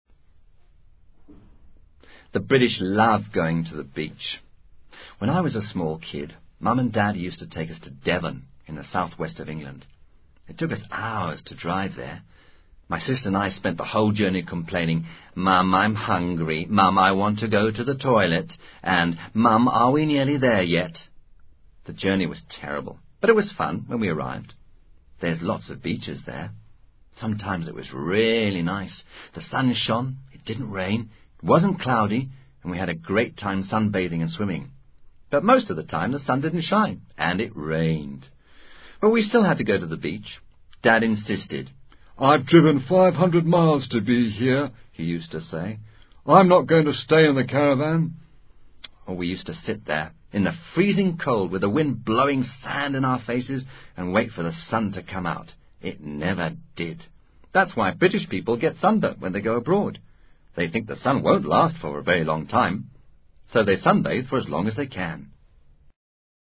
Este diálogo es útil para reforzar, además de la comprensión auditiva, el uso de las siguientes estructuras de la lengua inglesa: Past simple negatives, modal negatives, used to, would for past habit.